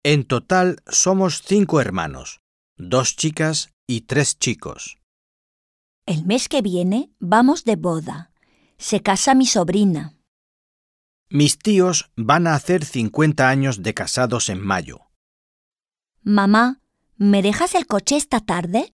Un peu de conversation - La structure familiale